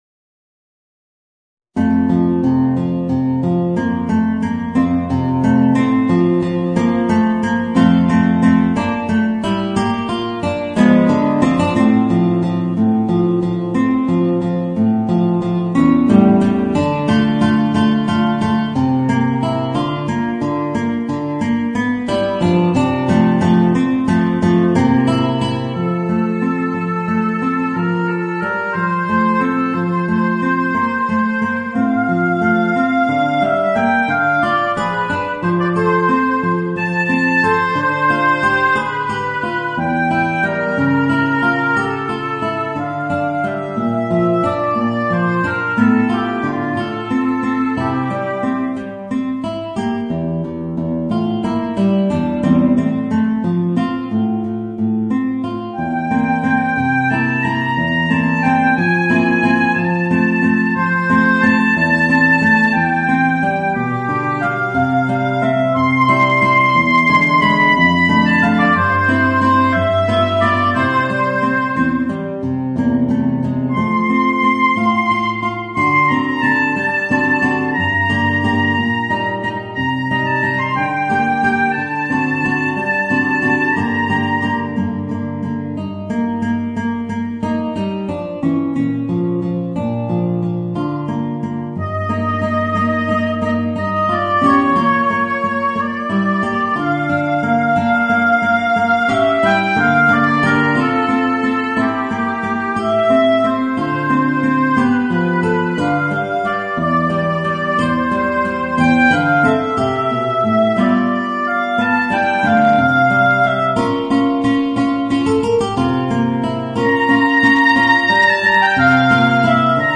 Voicing: Oboe and Guitar